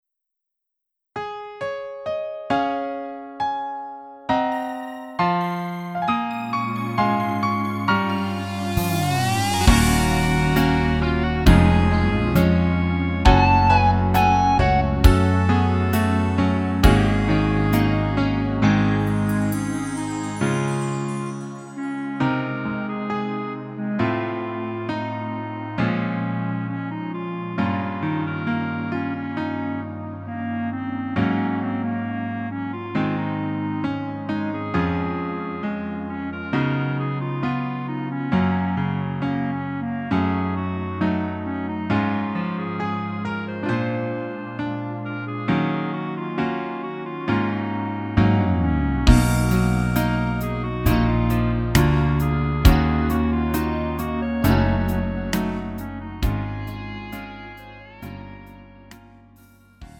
음정 원키 4:23
장르 가요 구분 Lite MR